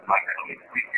These EVPs Mention Our Names
during an investigation at the Music Hall in Portsmouth N. H.